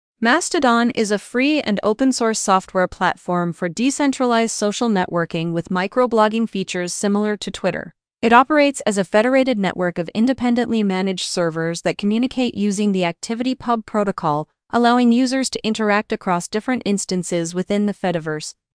Here's a demo of one of the voices reading about Mastodon.